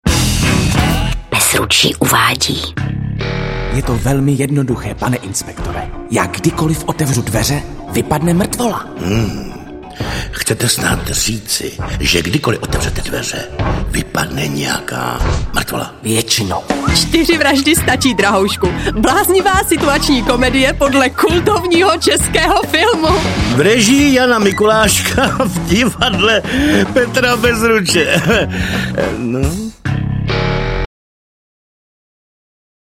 Audio-upoutávka na inscenaci Čtyři vraždy stačí, drahoušku